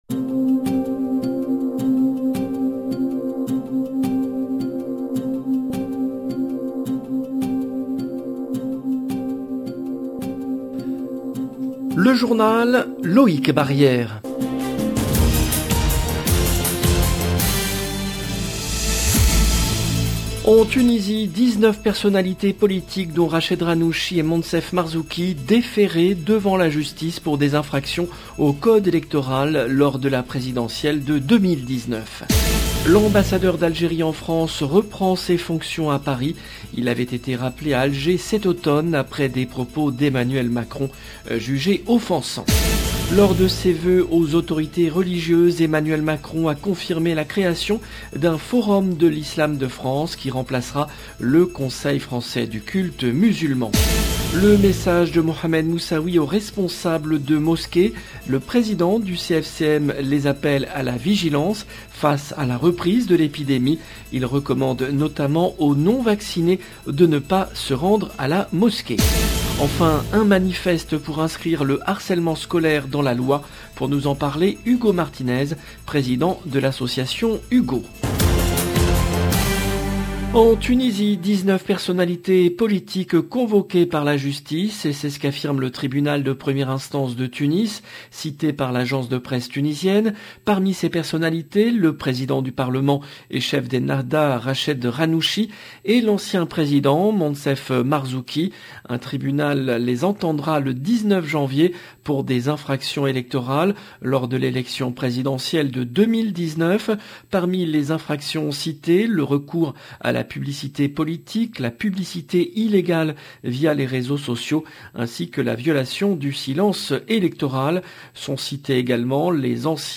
LE JOURNAL DU SOIR EN LANGUE FRANCAISE DU 6/01/22